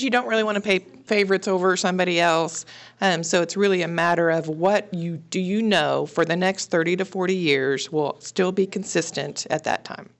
During their recent study meeting Wednesday, commissioners discussed the new logo that will adorn the soon-to-be updated East 12th Ave. elevated water storage tank.
Mayor Becky Smith says that is a difficult task considering there are so many things that are synonymous with Emporia.